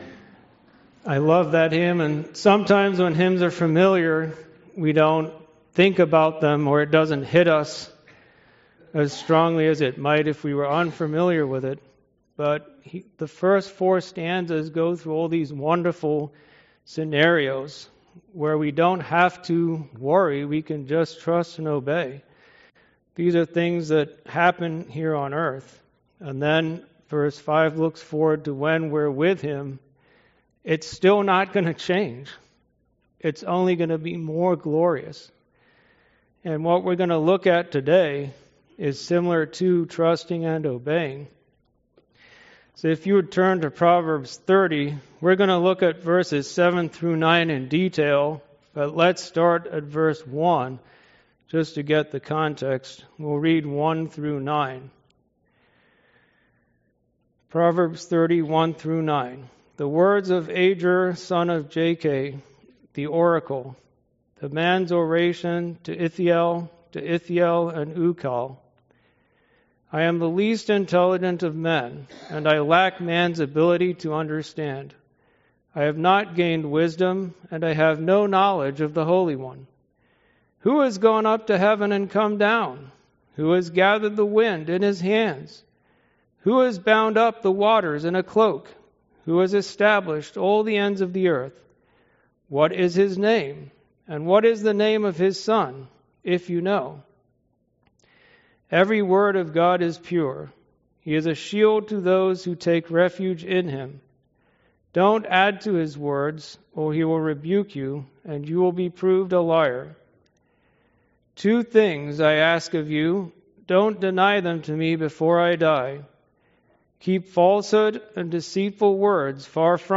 Names of God Passage: Ezekiel 48 Service Type: Sunday School « Sorrento